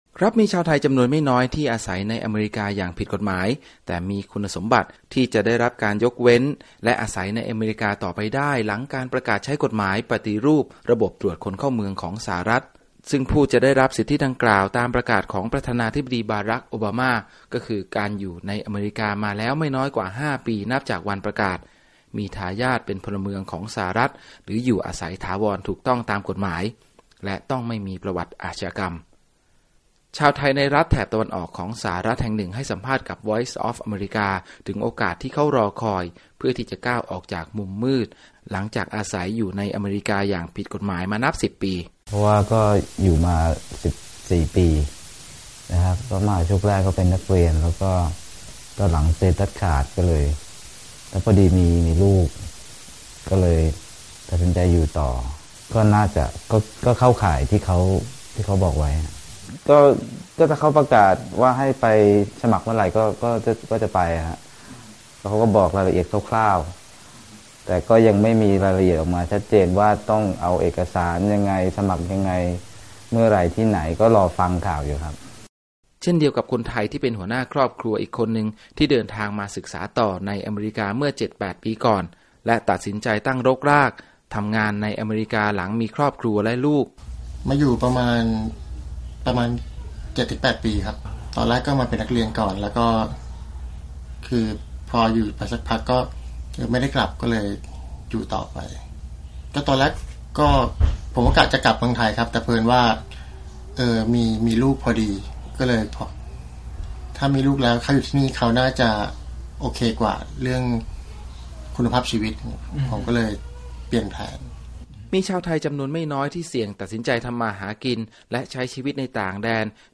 ชาวไทยในรัฐแถบตะวันออกของสหรัฐแห่งหนึ่งให้สัมภาษณ์กับวีโอเอ ภาคภาษาไทยถึงโอกาสที่เขารอคอยเพื่อที่จะก้าวออกมาจากมุมมืด หลังจากอาศัยในอเมริกาอย่างผิดกฎหมายมานับสิบปี